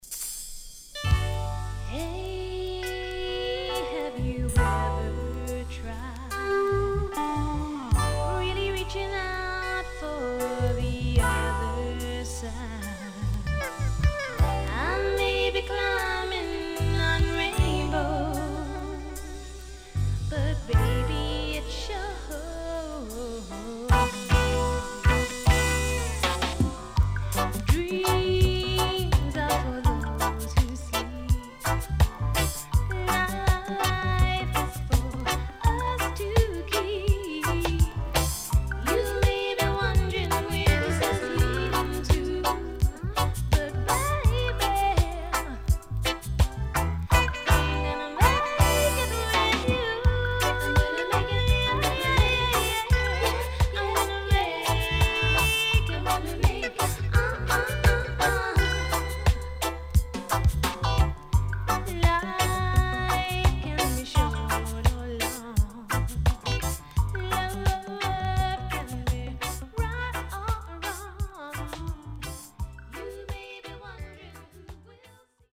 CONDITION SIDE A:VG(OK)〜VG+
【12inch】
SIDE A:所々チリノイズ、プチノイズ入ります。